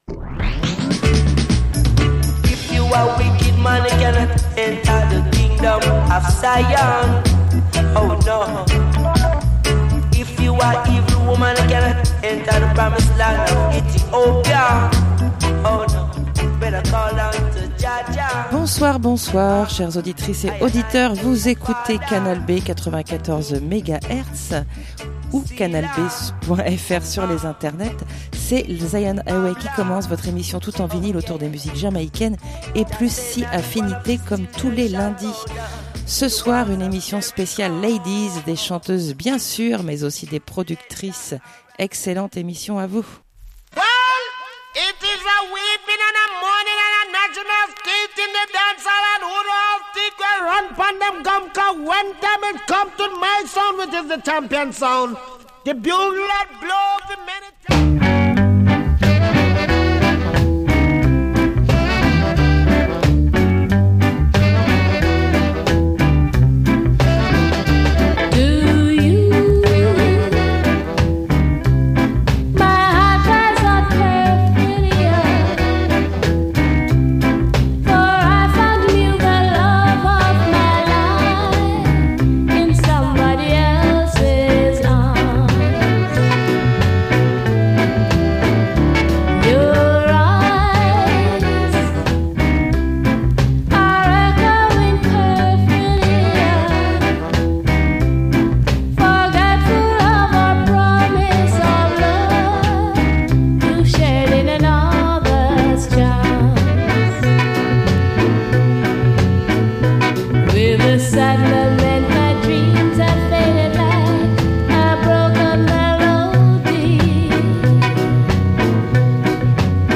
Émission spéciale Ladies, des chanteuses bien sûr! mais aussi productrices